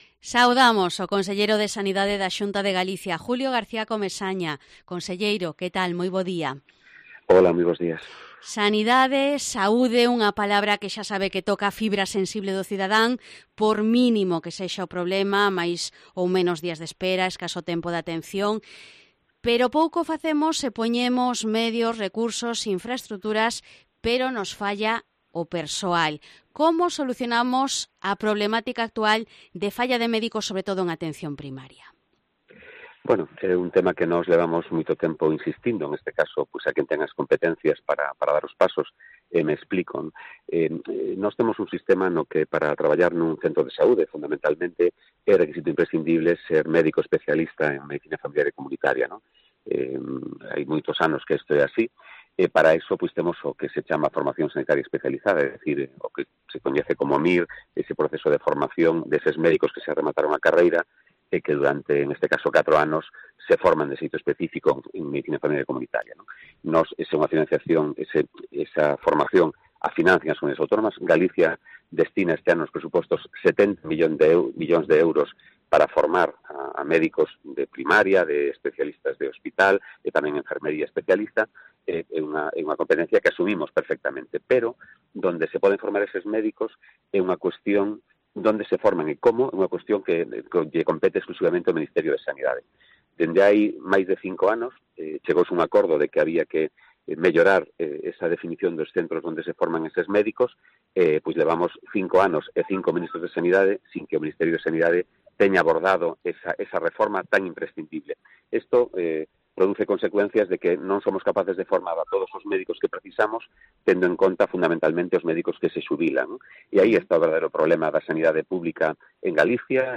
Entrevista Julio García Comesaña, conselleiro de Sanidade